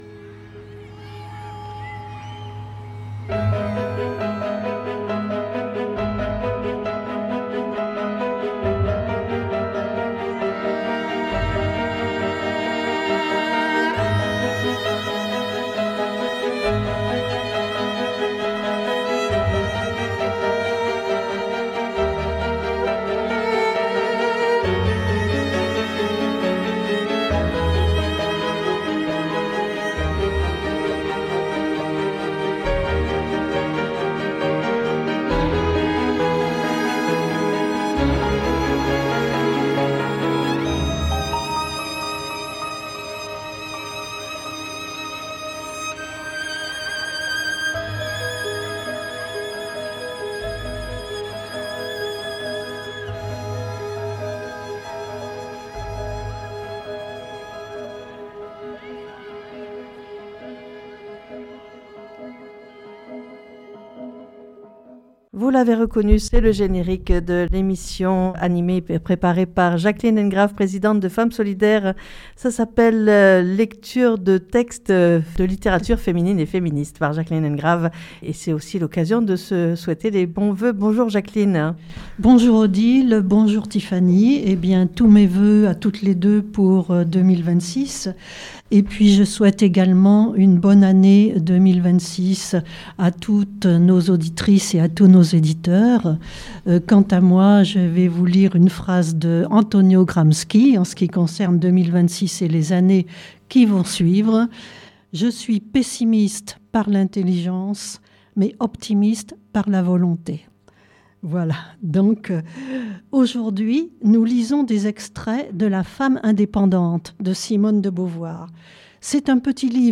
Lecture de textes de lilttérature féminine et féministe - Janvier 2026.mp3 (12 Mo)